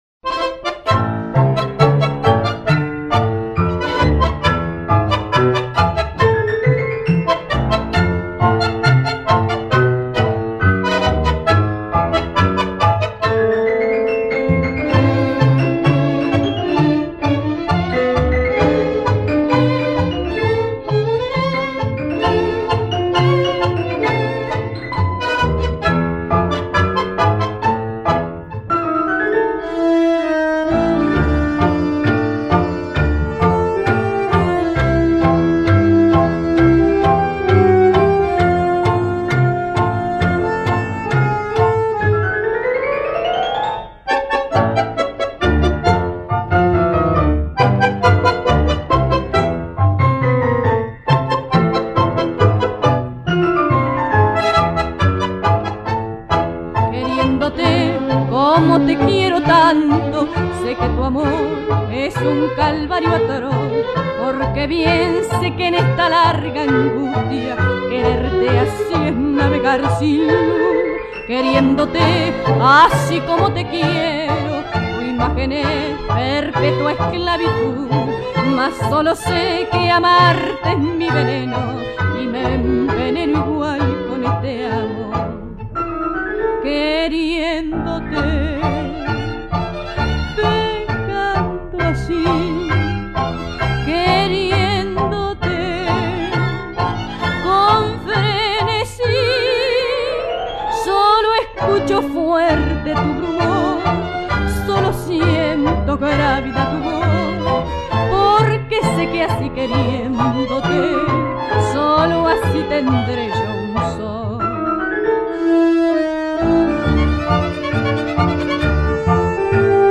La tanda di tango